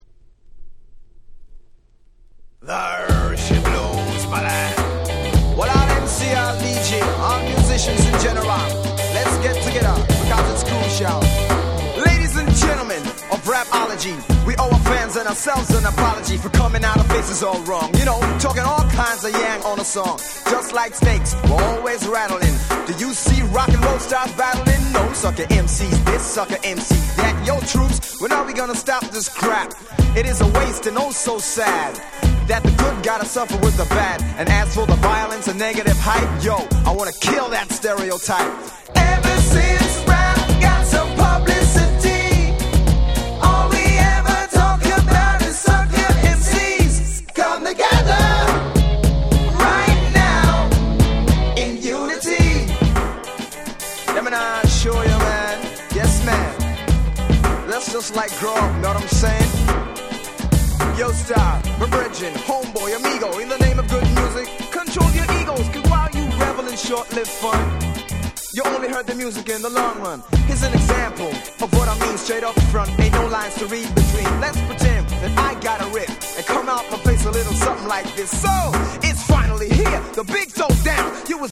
軽快なラガマフィンラップで非常に調子良いです！
80's ダンスホールレゲエ Reggae